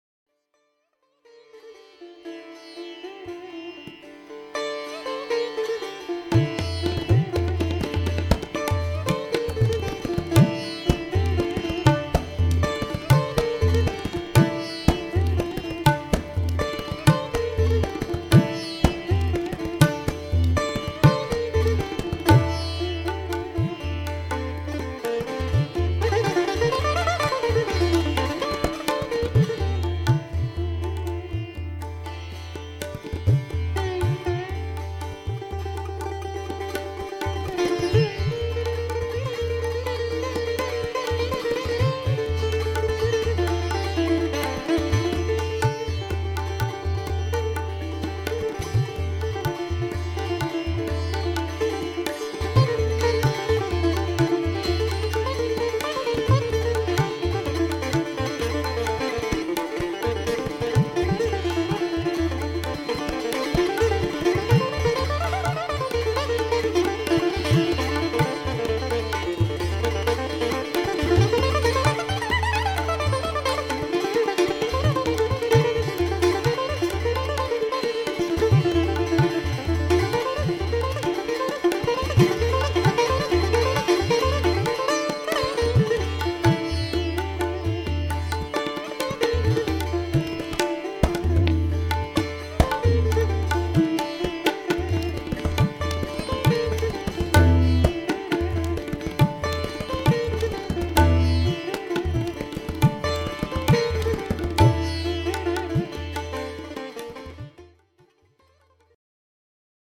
Indian Classical – SITAR